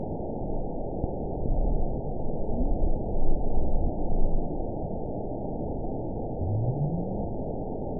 event 920550 date 03/30/24 time 00:34:25 GMT (1 year, 1 month ago) score 9.63 location TSS-AB01 detected by nrw target species NRW annotations +NRW Spectrogram: Frequency (kHz) vs. Time (s) audio not available .wav